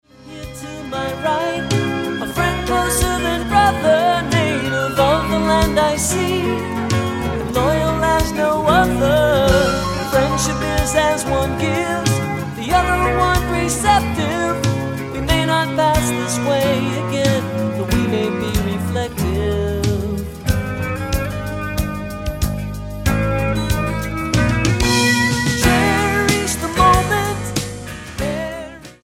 STYLE: Jesus Music